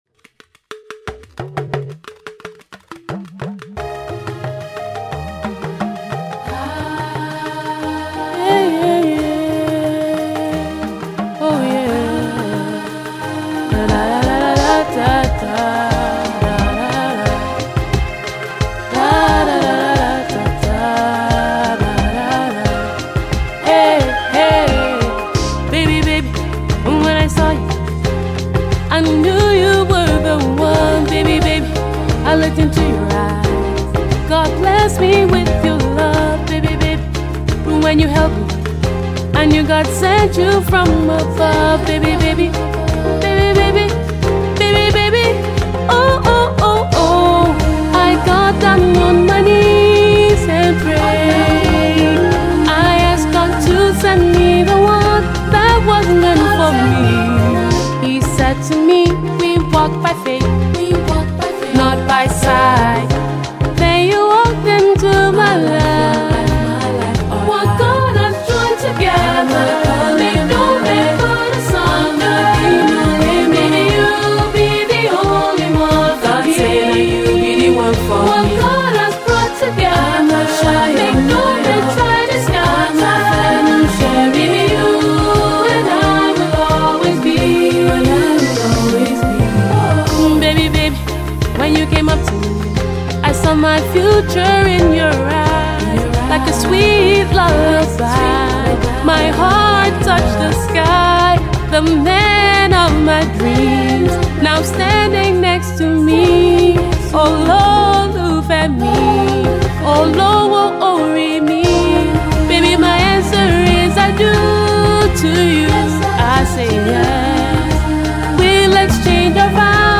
embodies the feelings and emotions of a fairy tale wedding